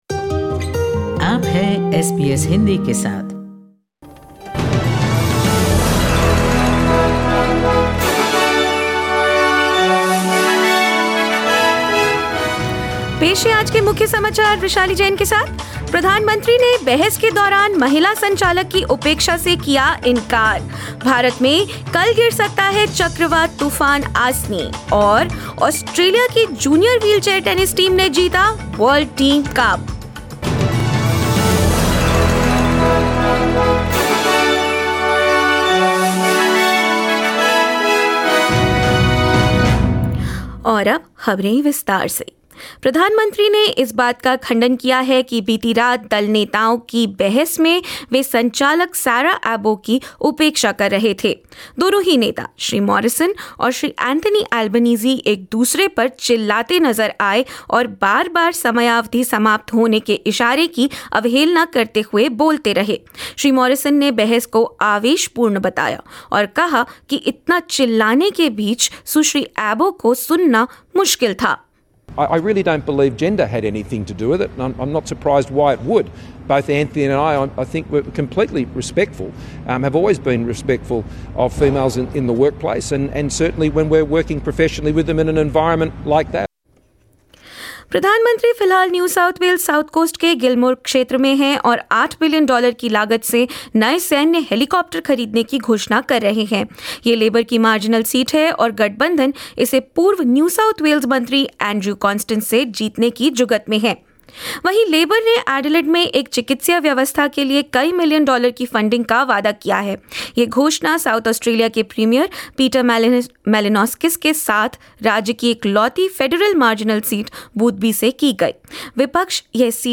In this latest SBS Hindi bulletin: early voting begins in Australia as opinion polls lean towards labor; In India, Cyclone Asani set to hit the western coast of the country; Australia's junior wheelchair tennis team have defeated Great Britain to win the World Team Cup and more news.